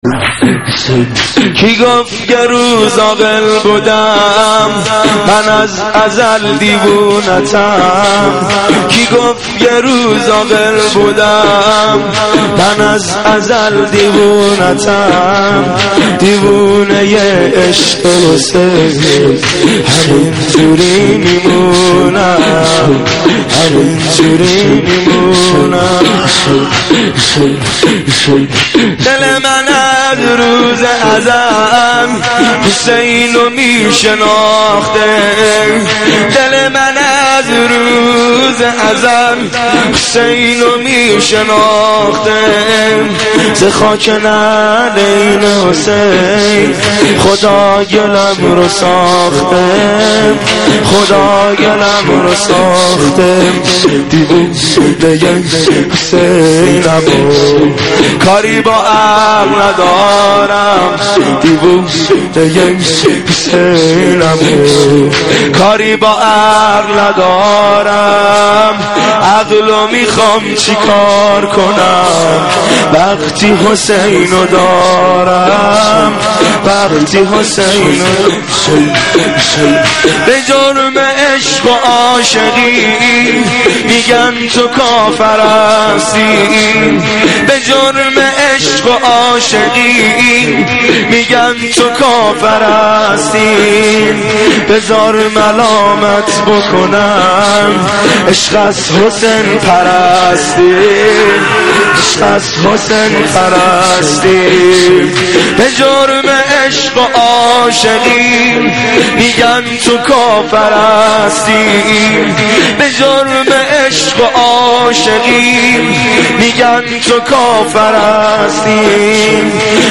(شور - علی اکبر سلام الله علیه)